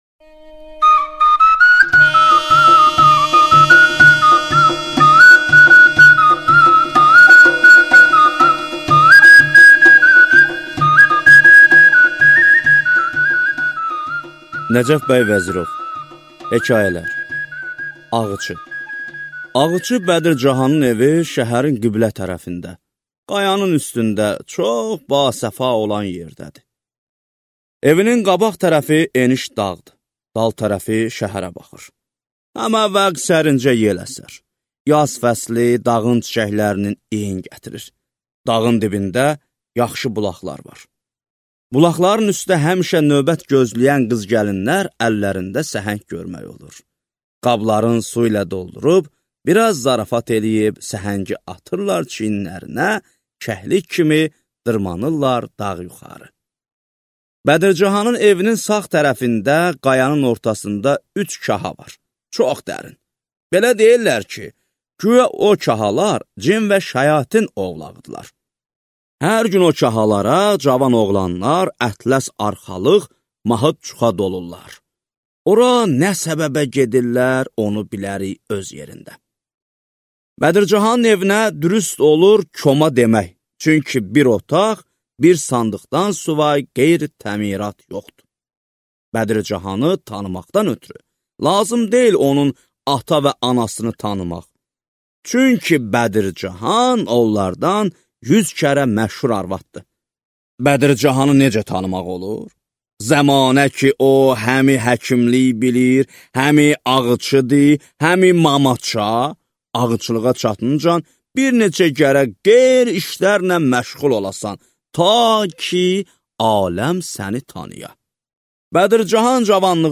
Аудиокнига Hekayələr və mütəfərriqələr | Библиотека аудиокниг